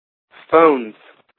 Ääntäminen
Ääntäminen US Haettu sana löytyi näillä lähdekielillä: englanti Käännöksiä ei löytynyt valitulle kohdekielelle. Phones on sanan phone monikko.